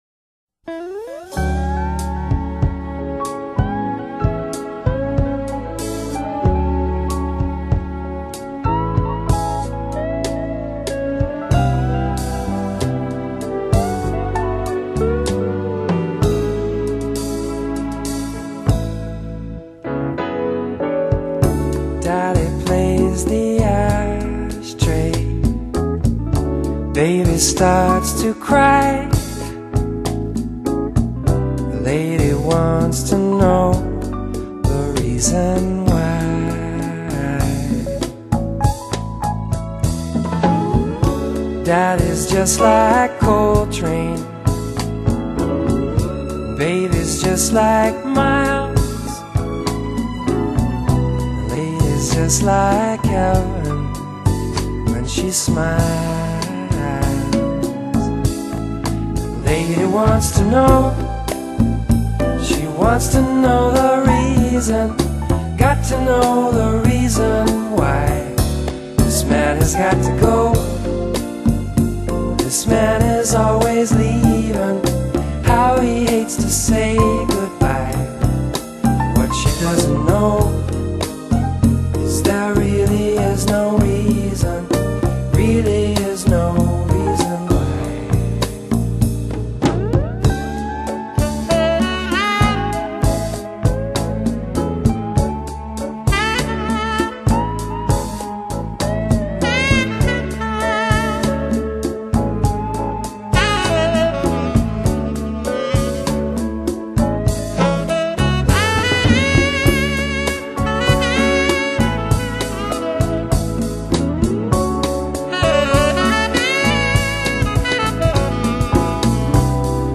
類型： 流行爵士